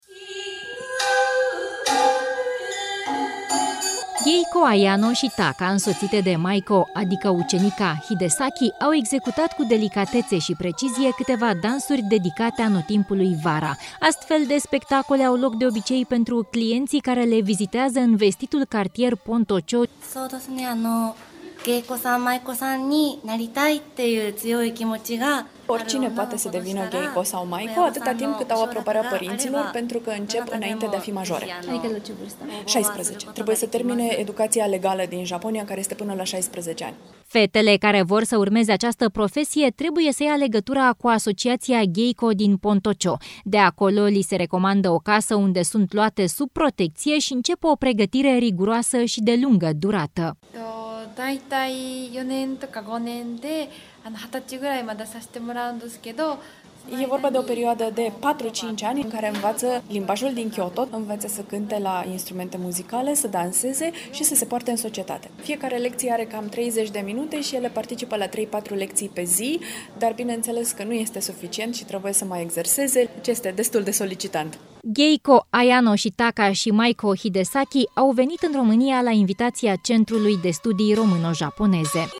Trebuie să termine educația legală din Japonia care este până la 16 ani”, spune una dintre ele.